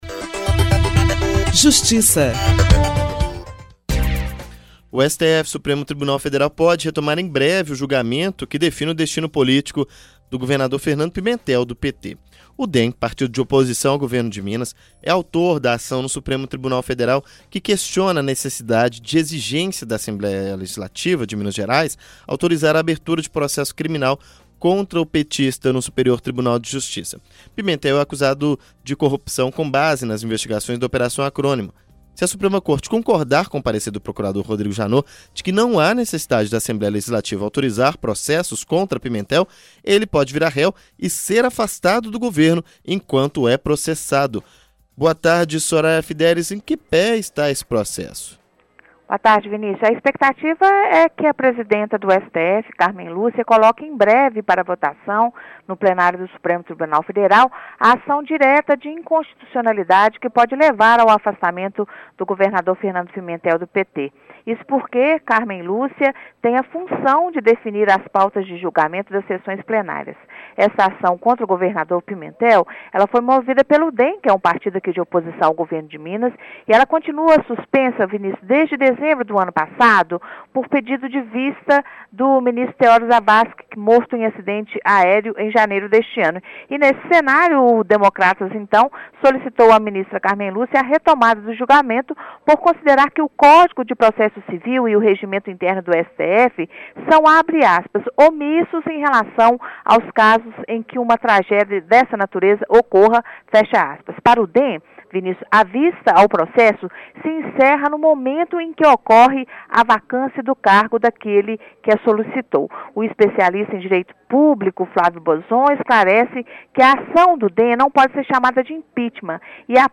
Jornalismo